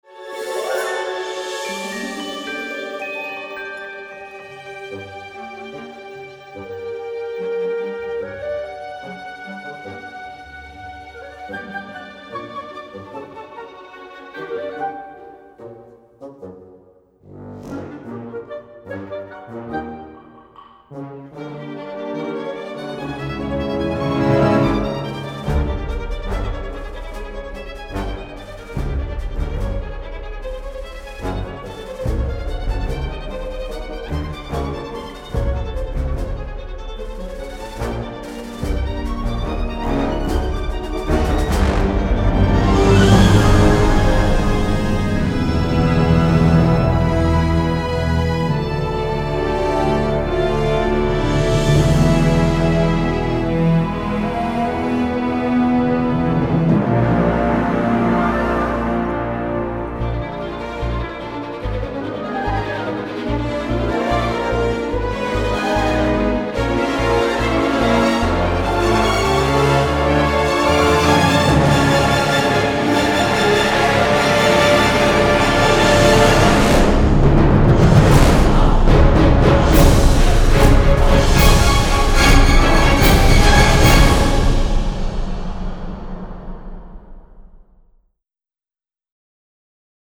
original motion picture score to the all-new
wildly fun and exhilarating music score